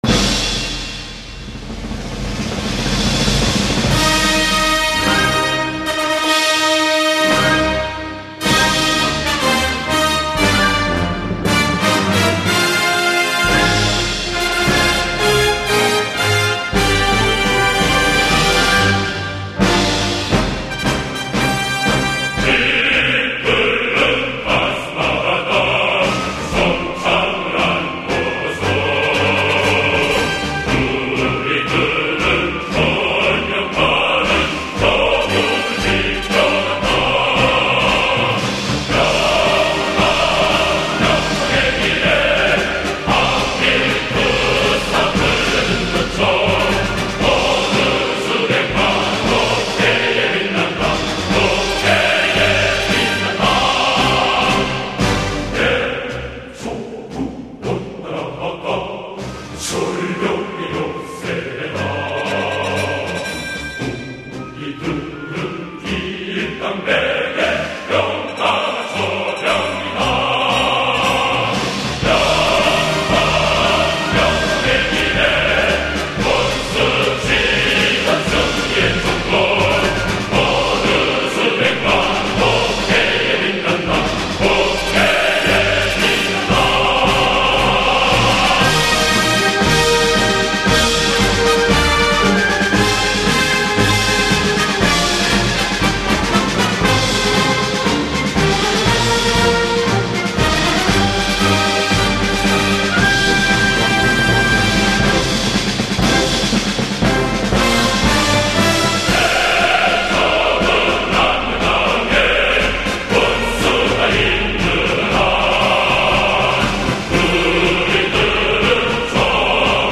Описание: Ещё один добротный северокорейский марш.